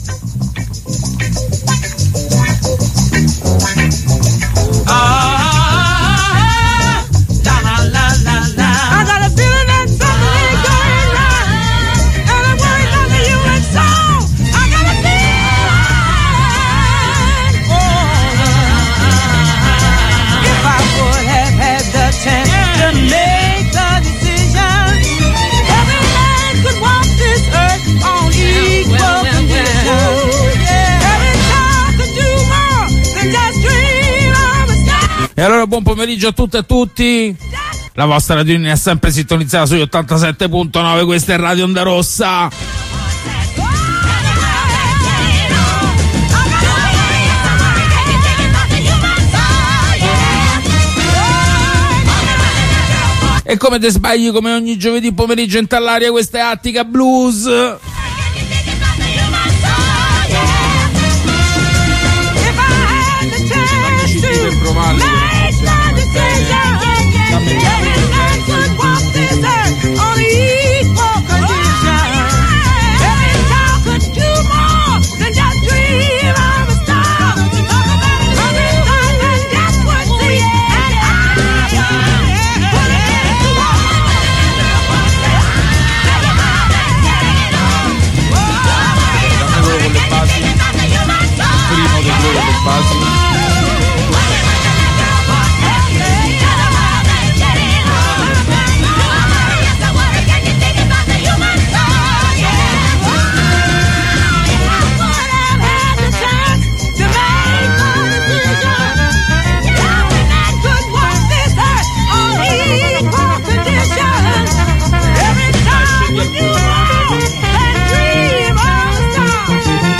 Cool Jazz